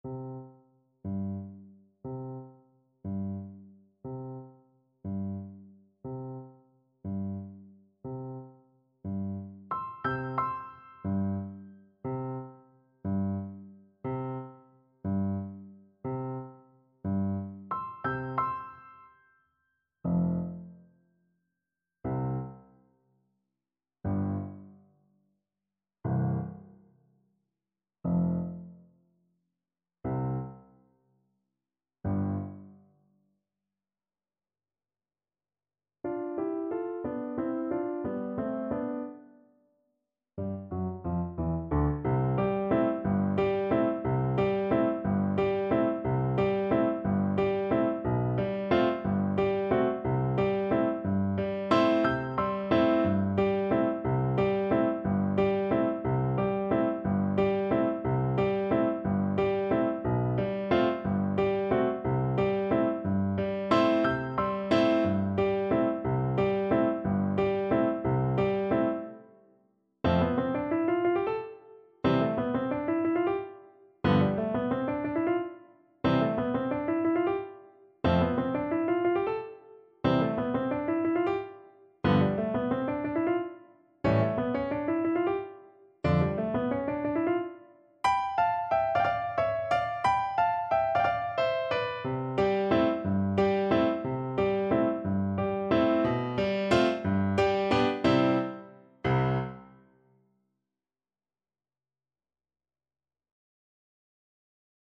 6/8 (View more 6/8 Music)
Pochissimo pi mosso = 144 . =60
C major (Sounding Pitch) (View more C major Music for Oboe )
Classical (View more Classical Oboe Music)